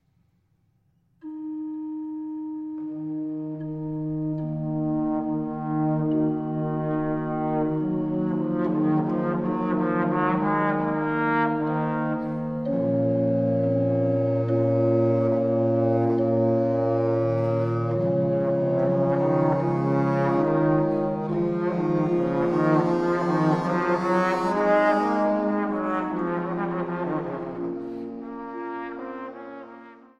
Posaune